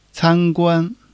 can1--guan1.mp3